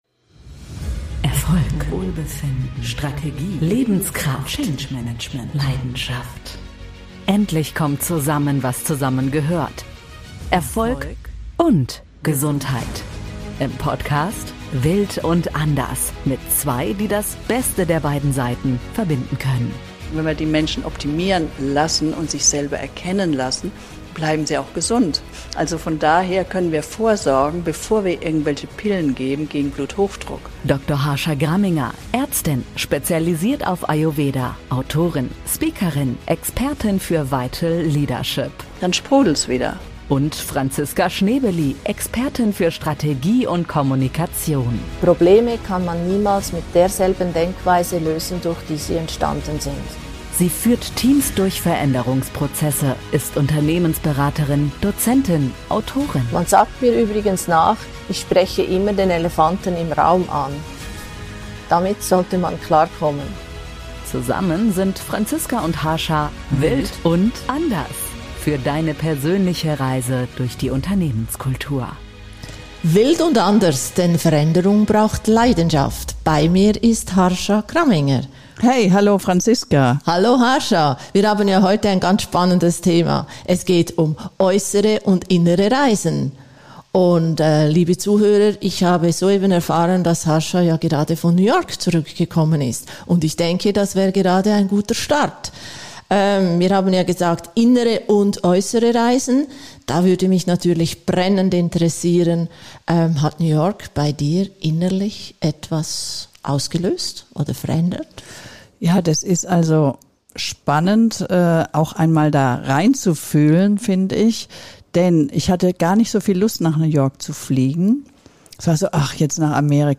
Woher kommt die Leidenschaft für andere Kulturen? Wie verändern Reisen unser Selbst? Welche Kräfte wirken auf uns, wenn wir unser gewohntes Umfeld verlassen? All diese Fragen beantworten die Zwei in ihrem Gespräch.